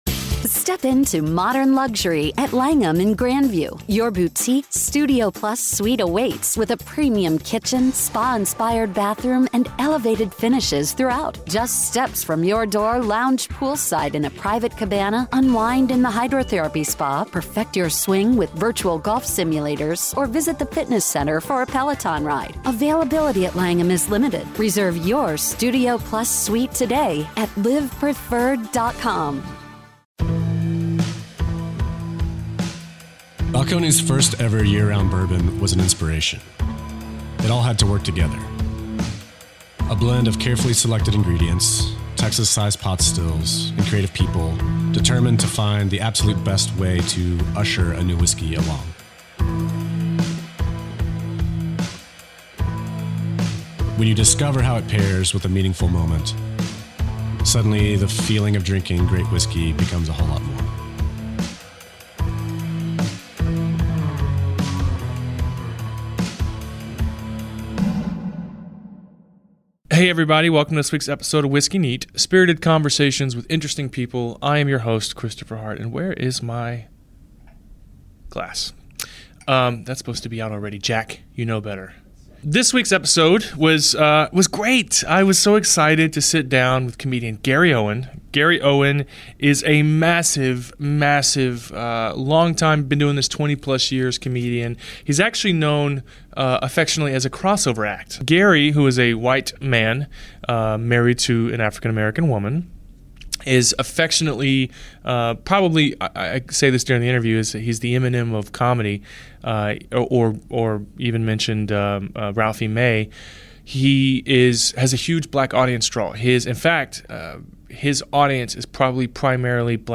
This week I am honored to sit down with long time comedian Gary Owen.